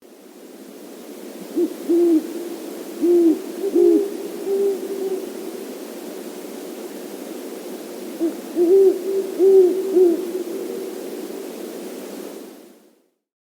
Hoot Owl
yt_osko5ZHReq8_hoot_owl.mp3